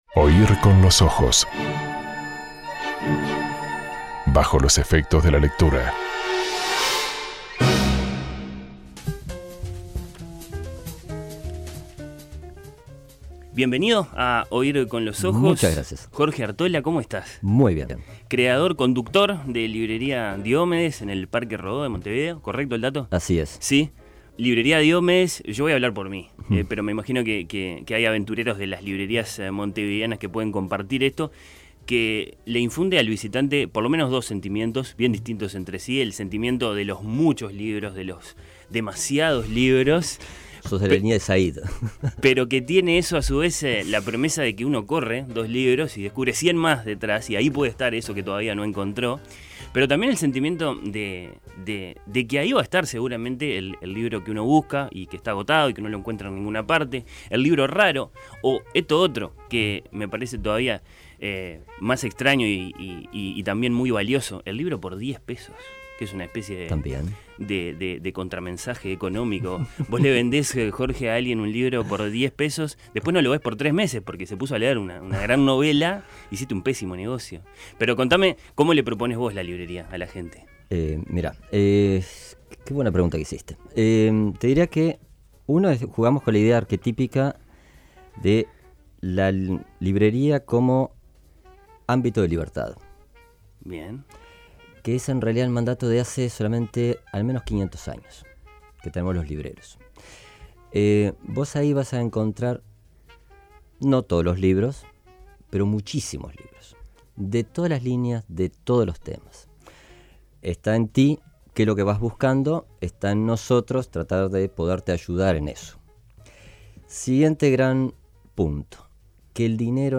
En este microciclo de entrevistas a protagonistas del mercado del libro en Oír con los ojos